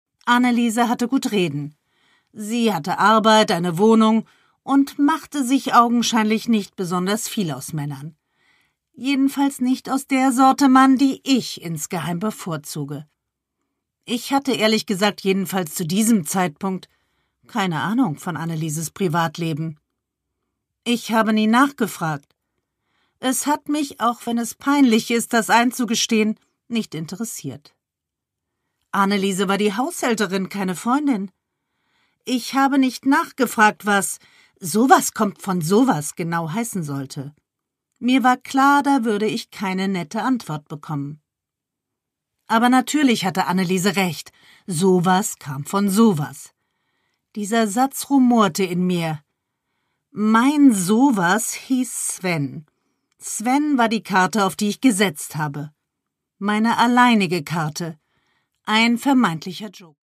Produkttyp: Hörbuch-Download
Gelesen von: Susanne Fröhlich